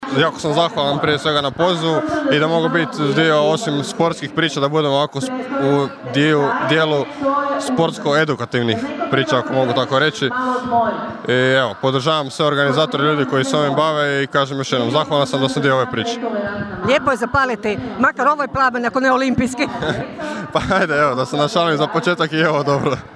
Pozdravi i svečano paljenje plamena